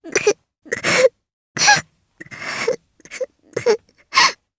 Rehartna-Vox_Sad_jp.wav